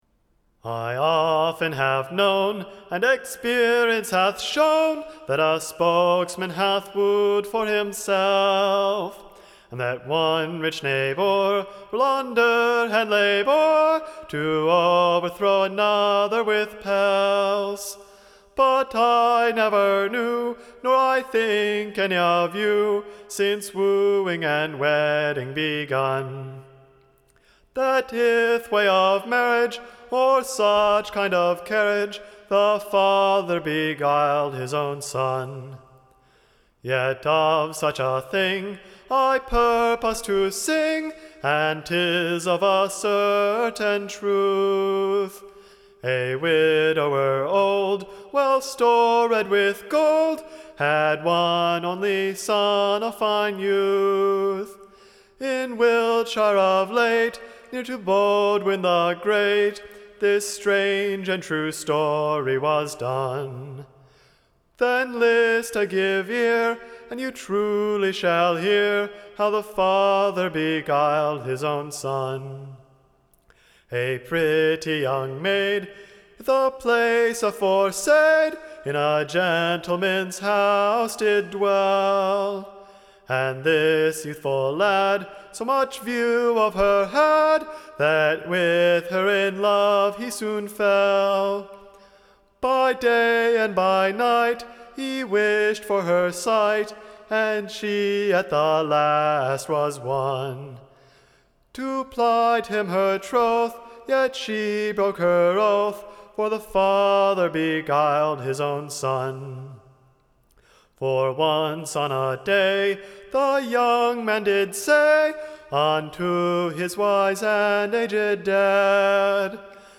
Recording Information Ballad Title The father hath beguil'd the sonne.
Tune Imprint To the tune of Drive the cold Winter away.